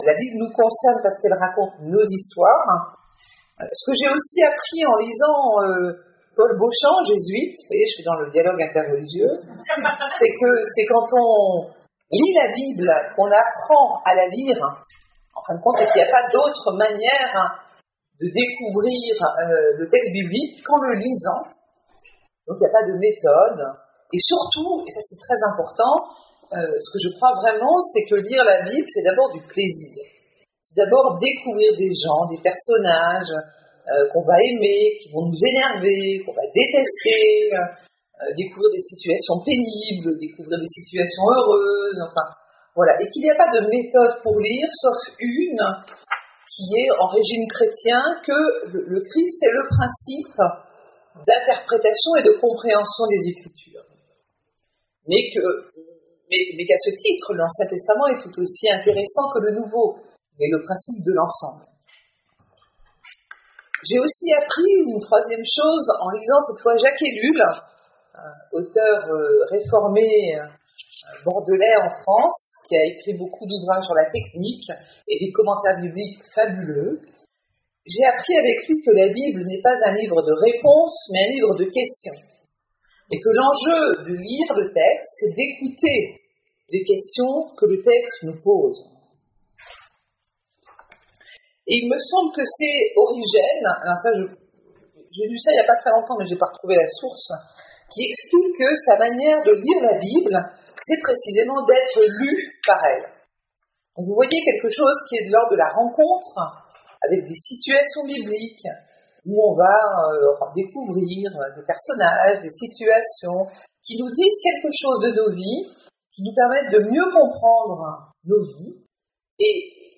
Bible Lire la Bible ou être lu par elle ? 10 Fév. 2023 de 19h00 à 21h00 Boulevard de Pérolles 38, Fribourg L'enregistrement de la conférence est disponible ci-dessous (voir lien sous l'image d'illustration). Au moyen d'une promenade à travers divers textes bibliques, nous verrons comment ces textes parlent de nous, et d'une certaine manière « nous lisent » et racontent notre histoire la plus concrète.